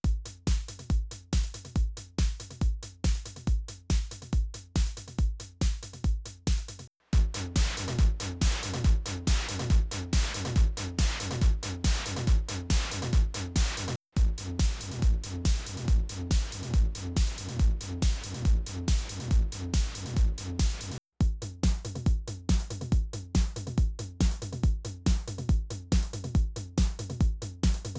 On all of the below, there are four channels from the TR-8: kick, snare/clap, toms/rim, hats.
The FX is EQ’d, with a slight low increase, slight cut at 125, and highs of 8k+ boosted.
Here’s a dry loop, then the Joyo Baatsin Crunchy, Rioter, and OCDrive: